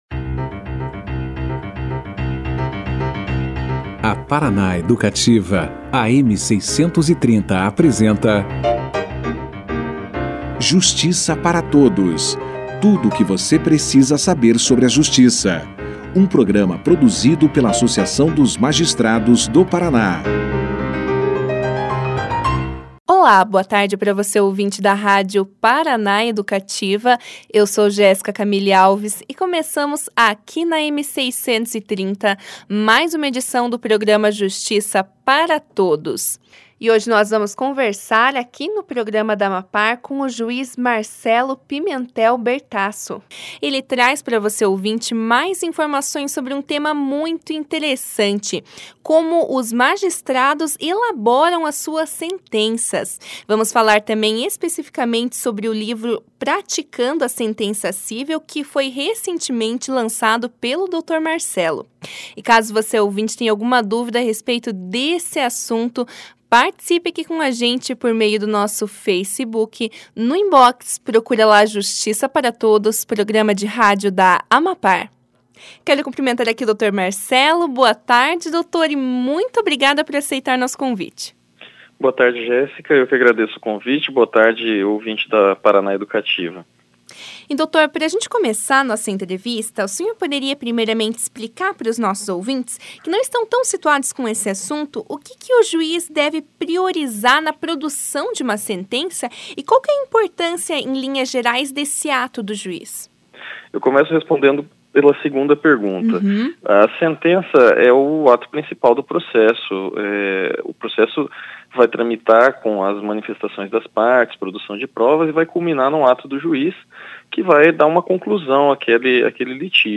Juiz Marcelo Pimentel Bertasso conversou com o Justiça para Todos, nesta terça-feira (4) e explicou aos ouvintes como os magistrados elaboram suas sentenças. O que deve ser priorizado pelo juiz ao produzir uma sentença, motivos pelos quais situações parecidas são julgadas de maneiras diferentes e os principais desafios encontrados pelos magistrados no seu ofício, foram questões esclarecidas por Marcelo no começo da entrevista.